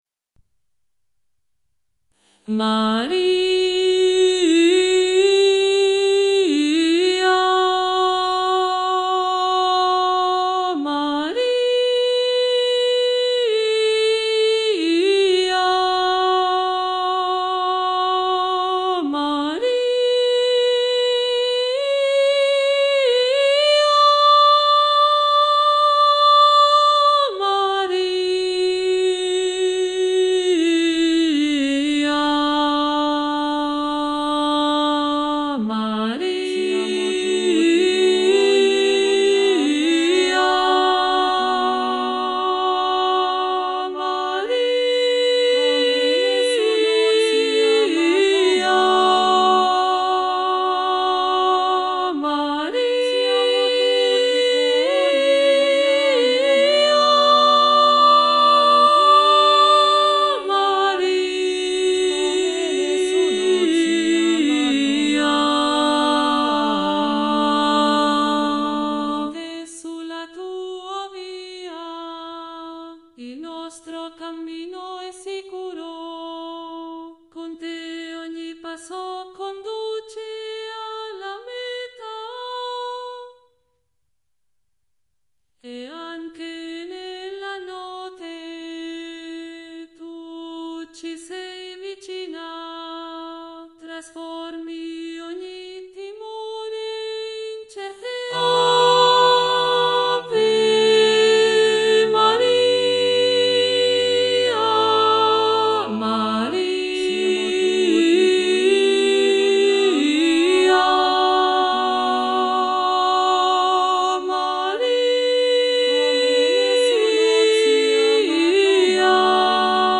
Maria_vogliamo_amarti-SOPRANI.mp3